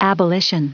Prononciation du mot abolition en anglais (fichier audio)
Prononciation du mot : abolition